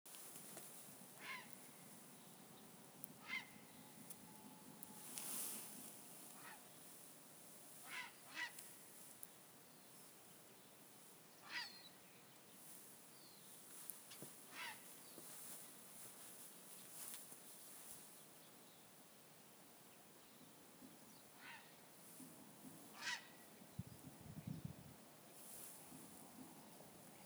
Birds -> Herons ->
Grey Heron, Ardea cinerea
StatusPair observed in suitable nesting habitat in breeding season